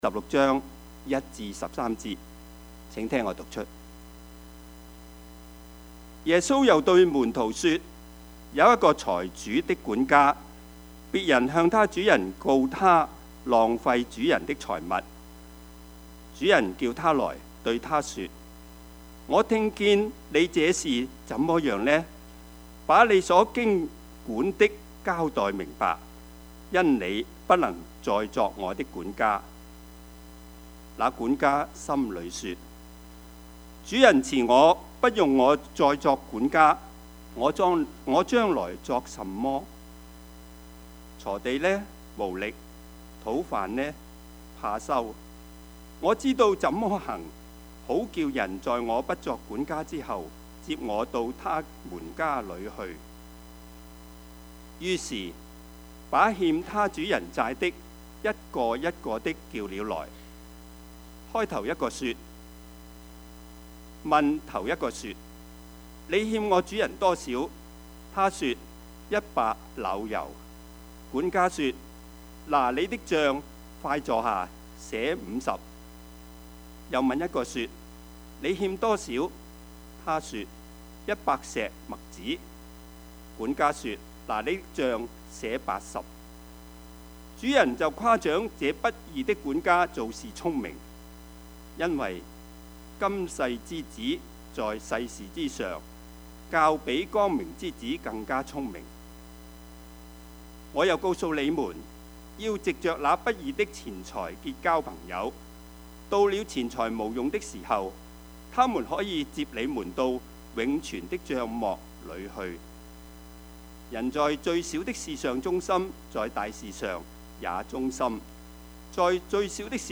Service Type: 主日崇拜
Topics: 主日證道 « 誰是真正的浪子?